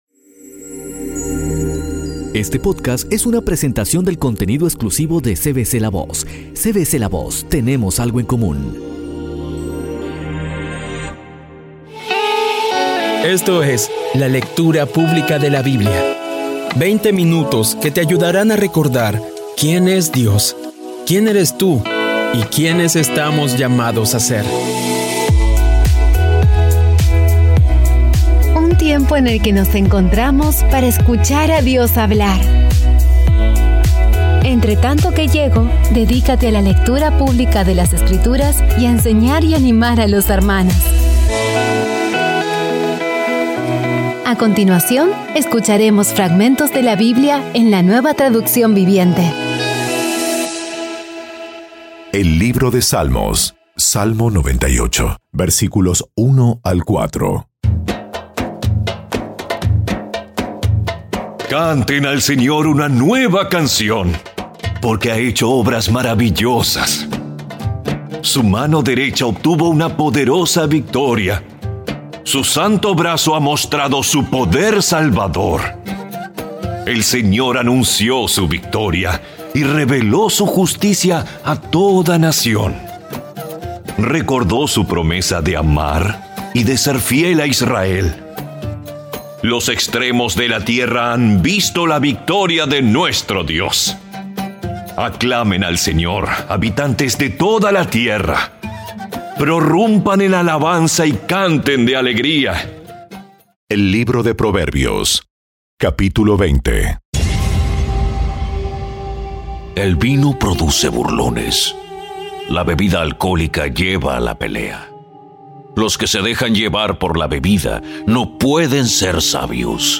Audio Biblia Dramatizada Episodio 237
Poco a poco y con las maravillosas voces actuadas de los protagonistas vas degustando las palabras de esa guía que Dios nos dio.